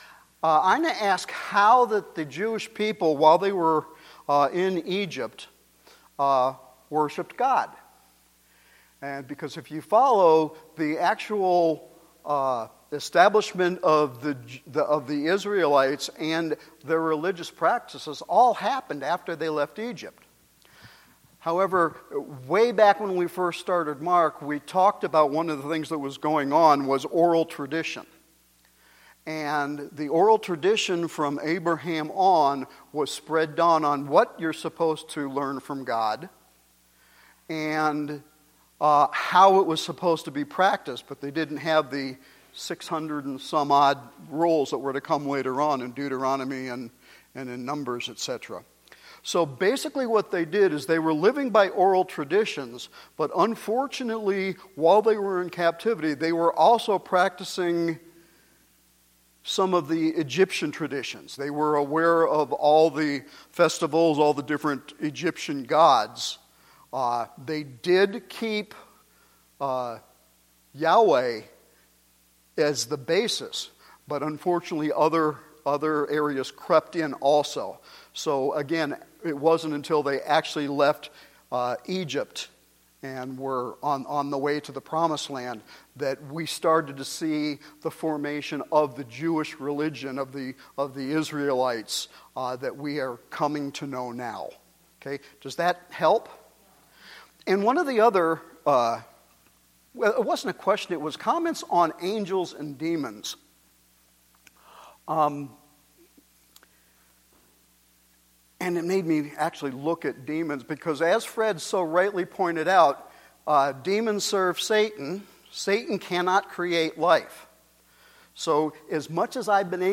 Our new Sunday evening Bible study continues with Mark Chapter 2.